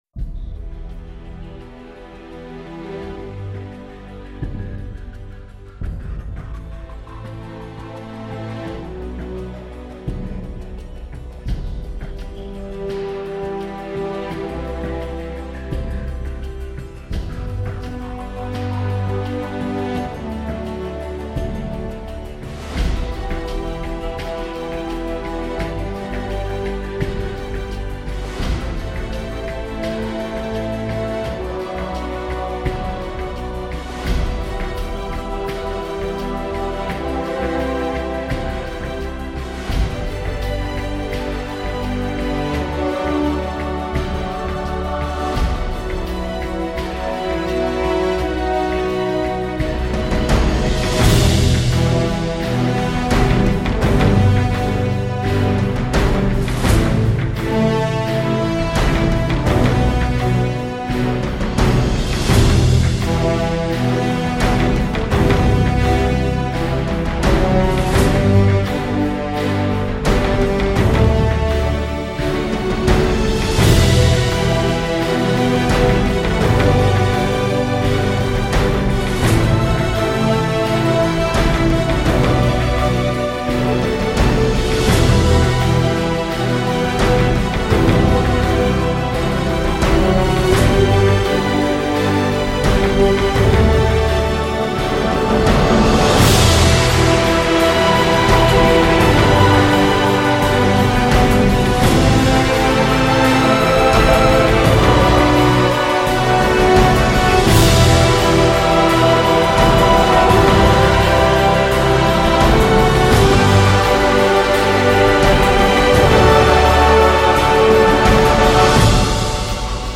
Genere: Instrumental – Fun.